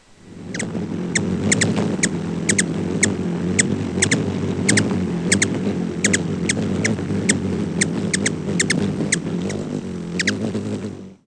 Ruby-throated Hummingbird Archilochus colubris
Flight call description A soft, squeaky "tchwt", often doubled or repeated in a series.
Bird in flight at feeder.
Diurnal calling sequences: